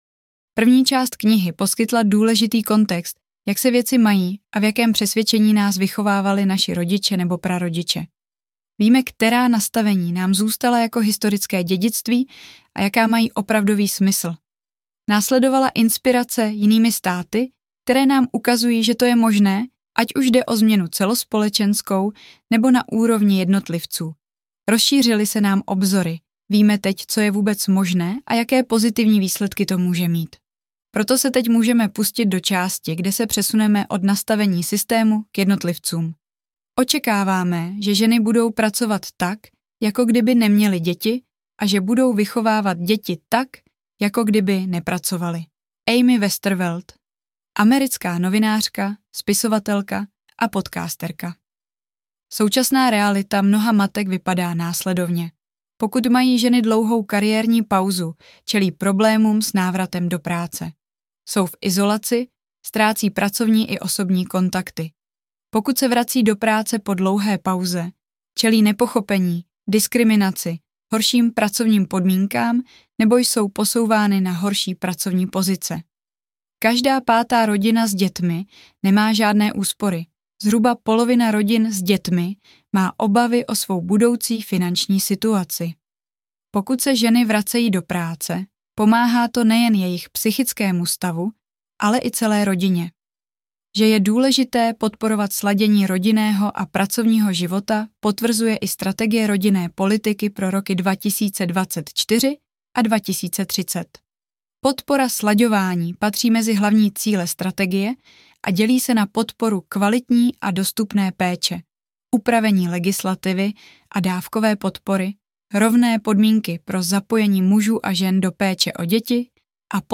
Audio knihaHolky to chtěj taky
Ukázka z knihy